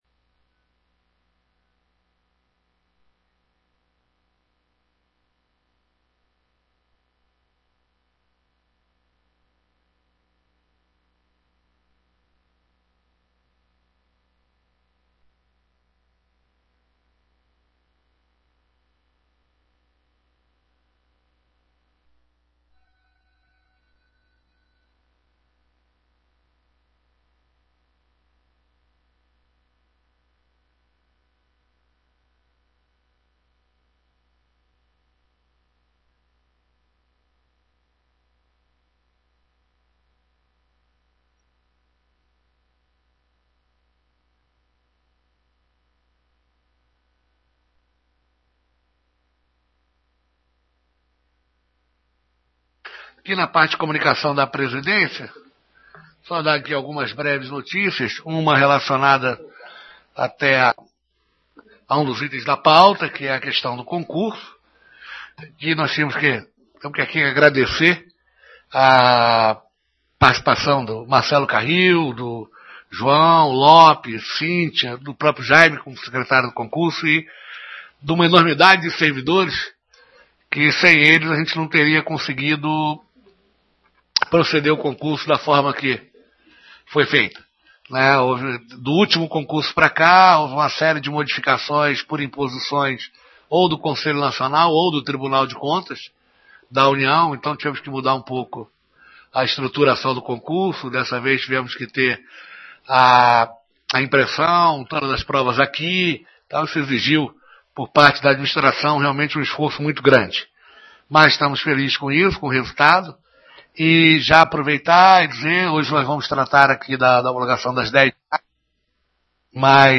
Em Sessão Ordinária realizada nesta manhã (13), na PGJM, o Conselho Superior do Ministério Público Militar, por unanimidade, opinou favoravelmente pela homologação do Resultado do 11º Concurso Público para Promotor de Justiça Militar – 11º CPJM, no qual foram aprovados 14 candidatos.